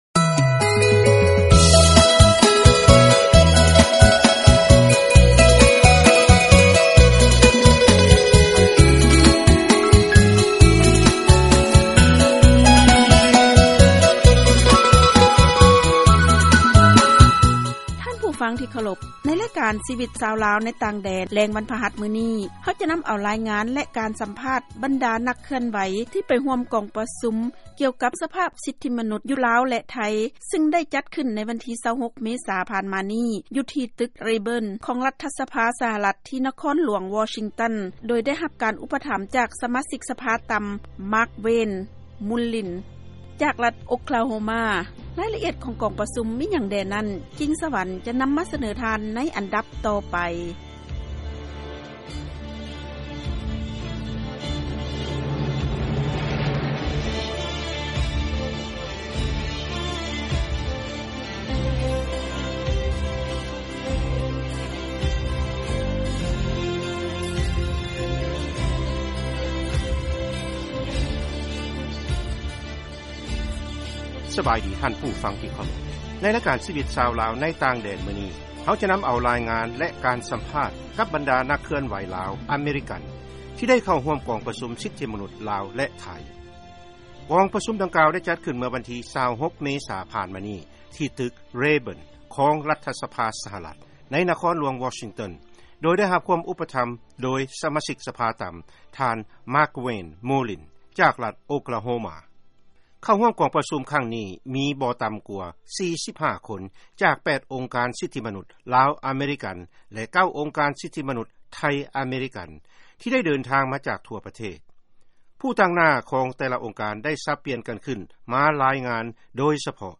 ເຊີນຟັງລາຍງານກອງປະຊຸມ ແລະການສຳພາດ ບັນດານັກເຄື່ອນໄຫວ ສິດທິມະນຸດ.